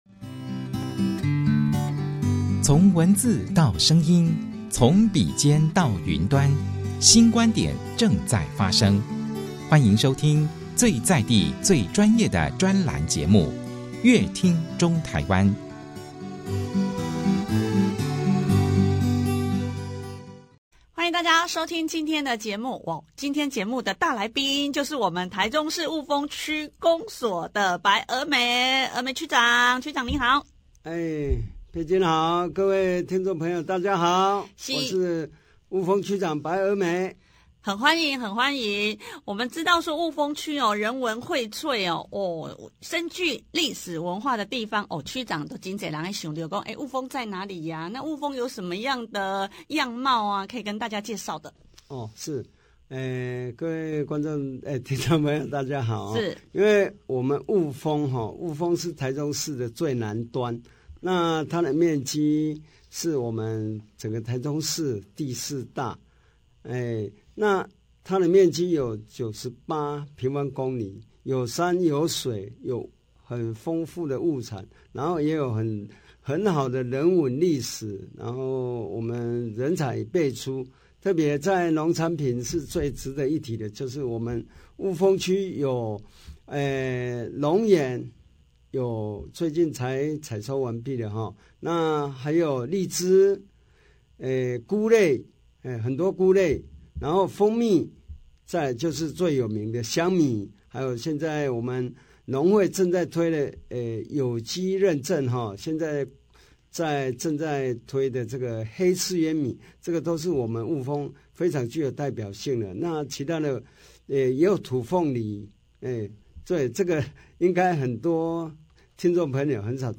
白區長特別在節目中賣關子，活動當天霧峰的吉祥物也會首次亮相、登場，想知道是什麼吉祥物，白區長邀約大家參加活動找答案。 另外霧峰區是台中市人文薈萃，深具歷史文化的地方，白區長也在節目中跟大家介紹霧峰的重要建設以及在地的銅板美食，加上霧峰的特色景點也是蘊藏許多亮點!想了解更多您所不知道的霧峰，快來收聽這集的節目喔!